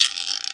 TM88 RippingPerc.wav